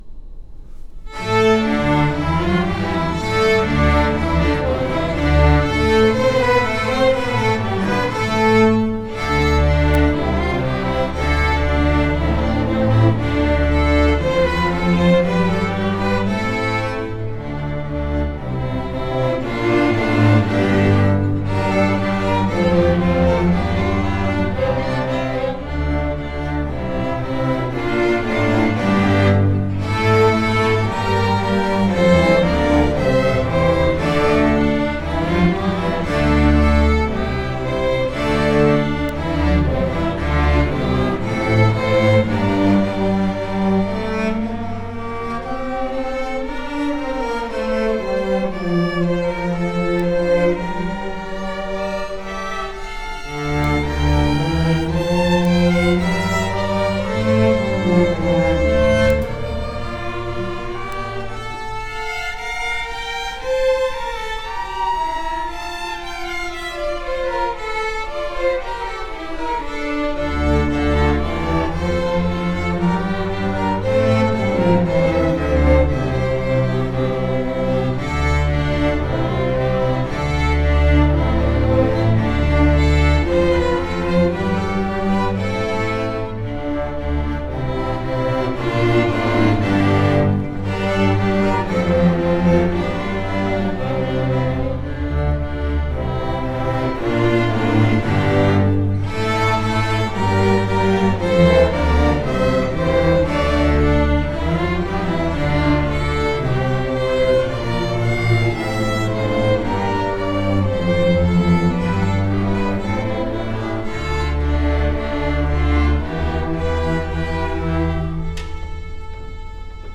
Orchestra District MPA 2020 – March 10 – 12 at Largo High School